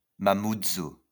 Mamoudzou (French pronunciation: [mamudzu]